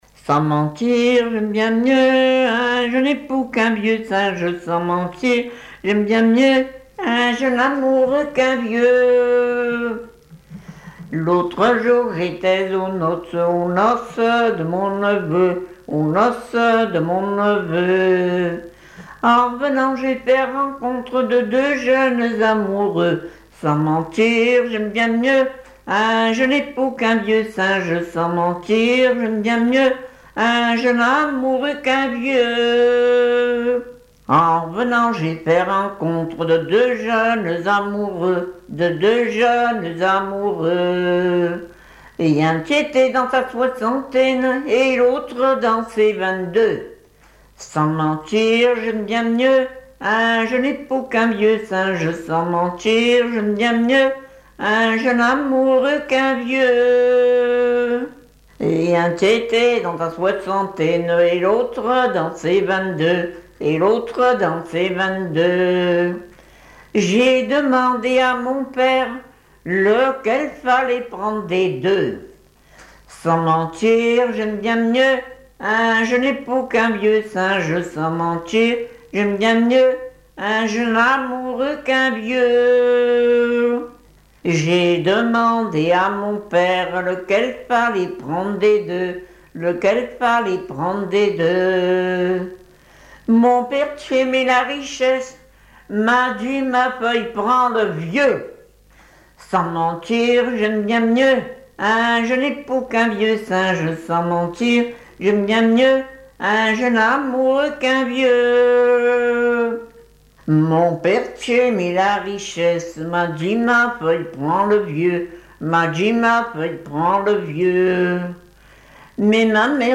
Chansons en dansant
Pièce musicale inédite